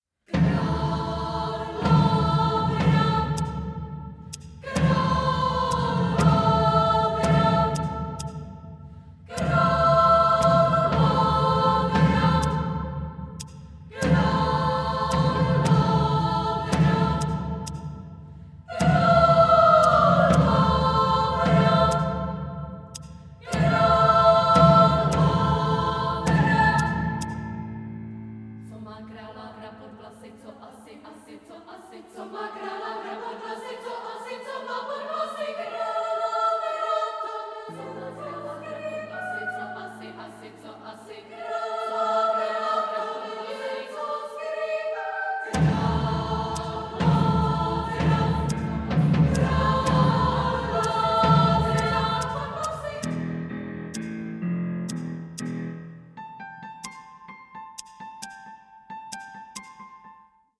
piano
percussion
scissors